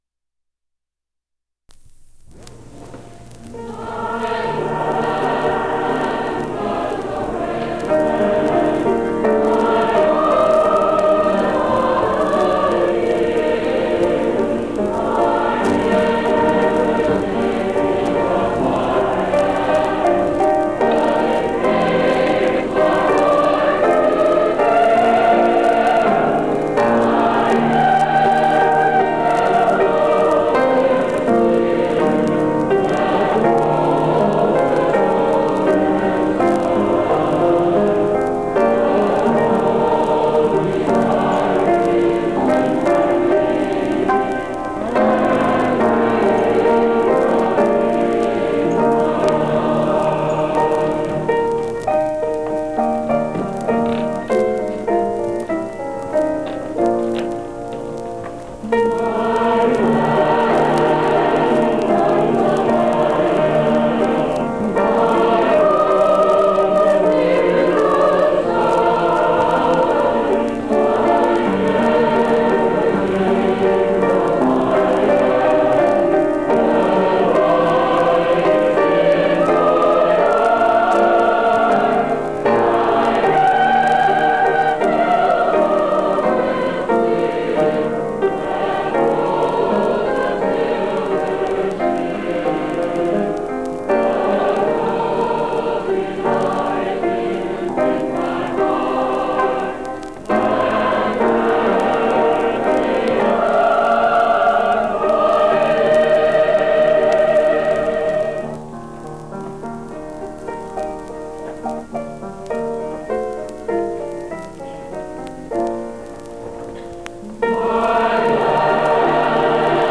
1955 Music in May chorus performance recording · Digital Exhibits · heritage
66e7846e744444aca985340ec10b102d69b73508.mp3 Title 1955 Music in May chorus performance recording Description An audio recording of the 1955 Music in May chorus performance at Pacific University. Music in May is an annual festival that has been held at Pacific University since 1948. It brings outstanding high school music students together on the university campus for several days of lessons and events, culminating in the final concert that this recording preserves.